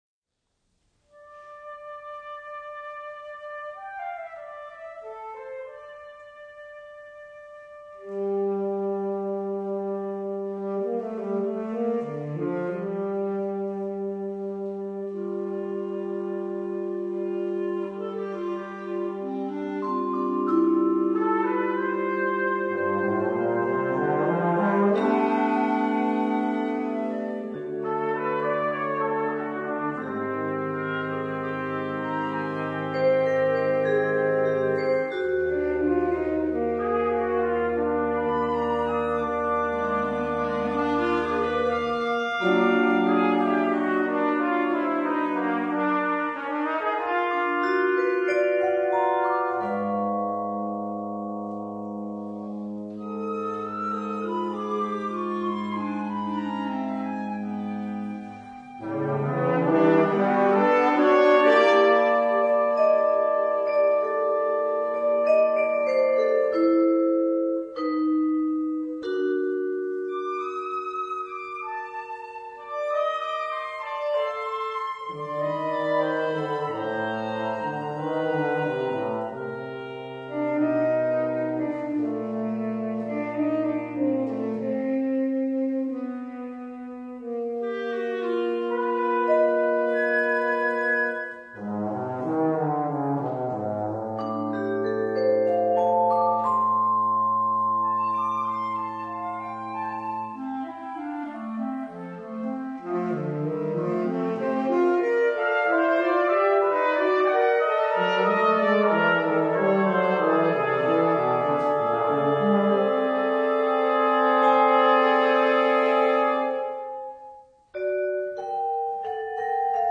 Written for Mixed Ensemble